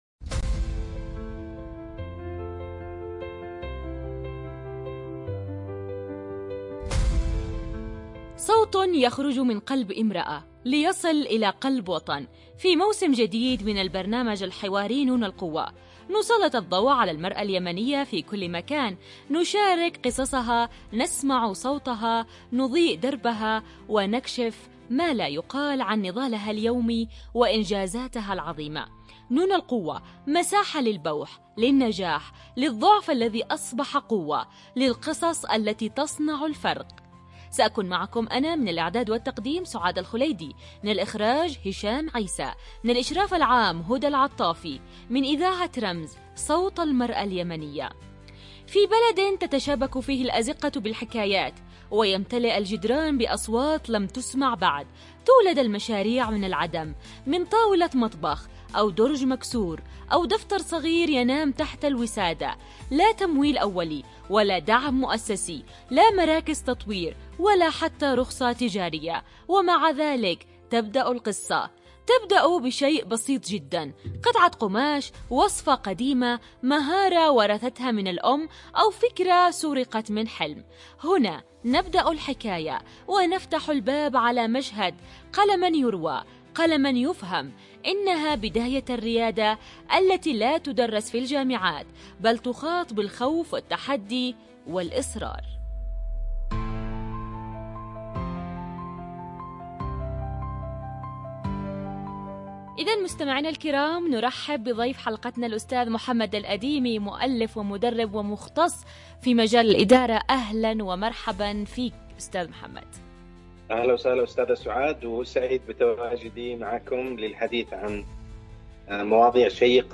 حلقة إذاعية حوارية